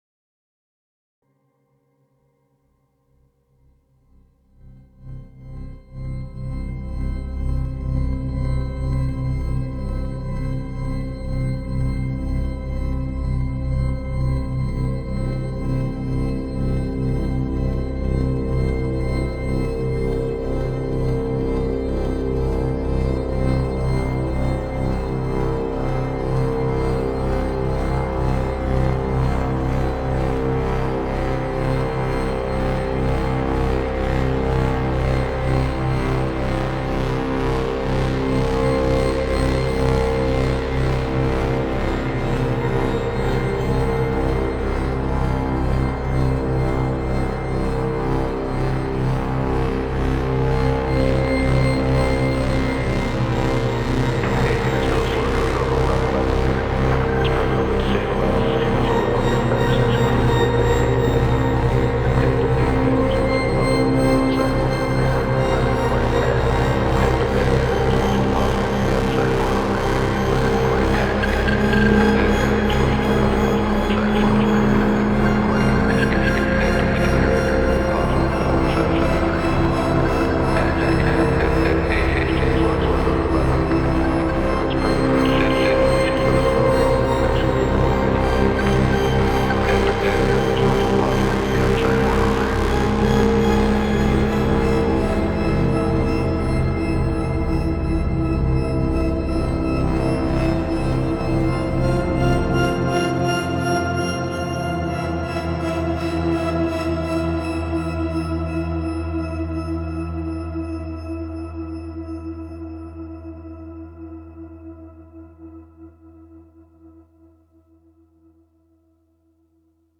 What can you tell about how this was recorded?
This is an intro for my live performance, recorded with SP404 and a bunch of effects